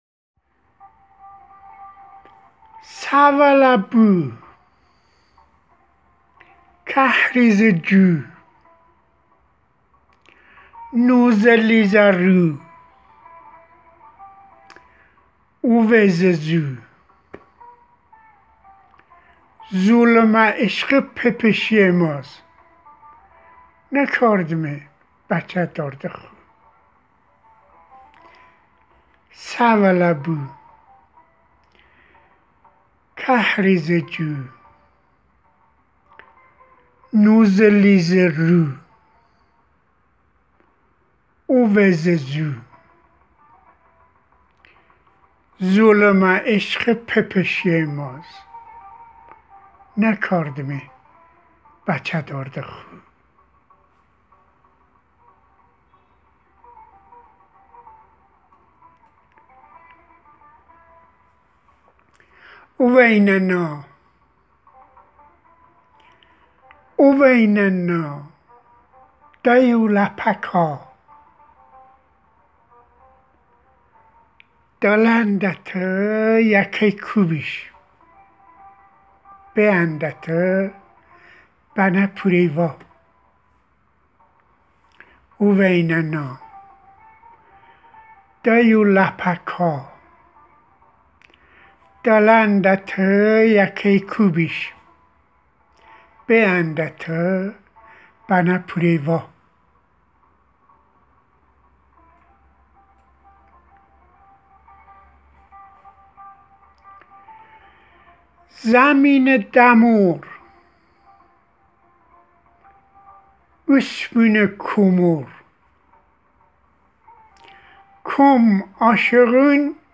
دکلمه زیبا چهار اثر اول